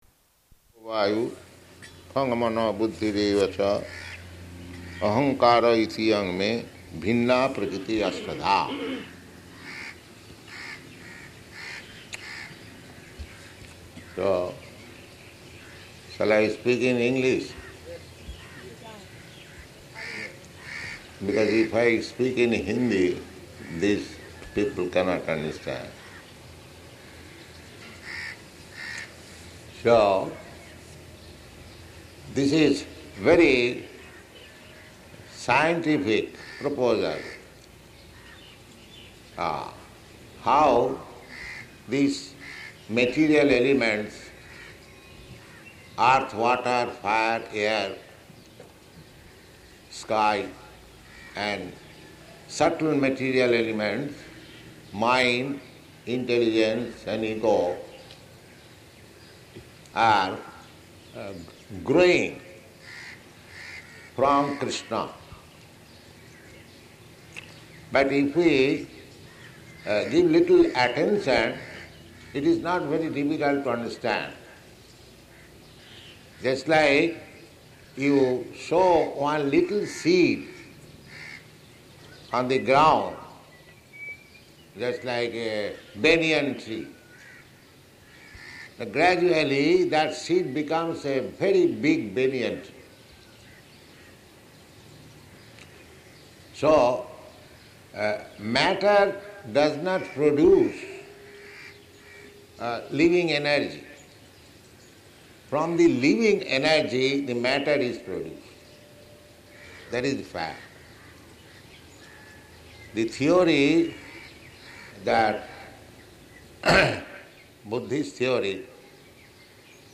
Type: Bhagavad-gita
Location: Bombay